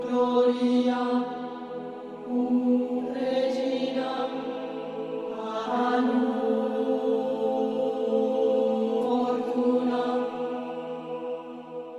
描述：AMINOR的合唱团循环；希望你觉得这个有用。
标签： 80 bpm Trap Loops Choir Loops 2.02 MB wav Key : A
声道立体声